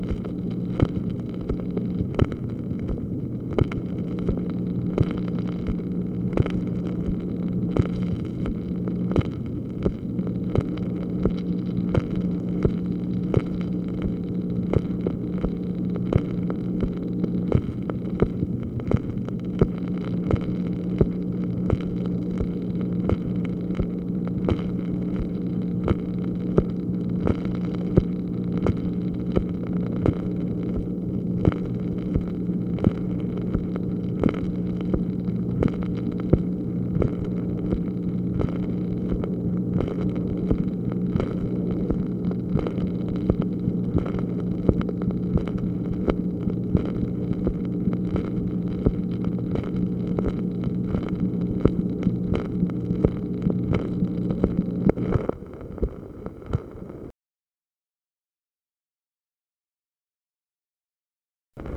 MACHINE NOISE, March 18, 1965
Secret White House Tapes | Lyndon B. Johnson Presidency